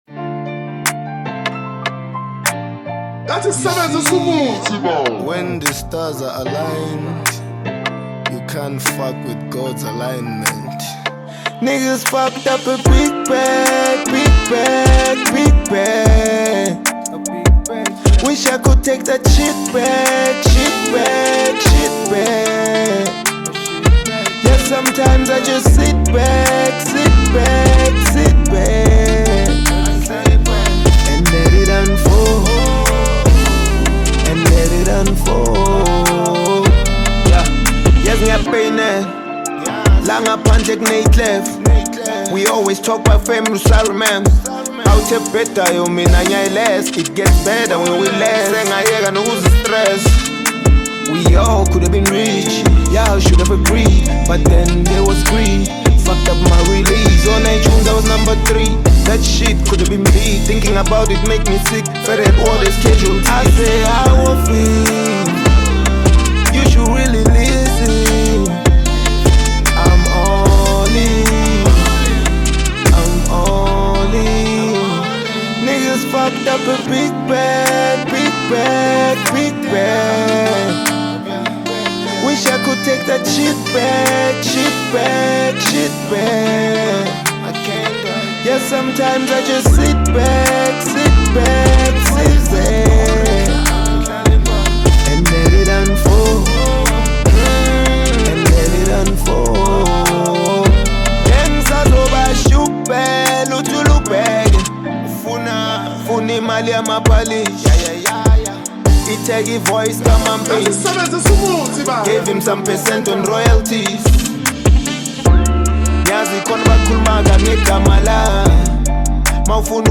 Hip Hop track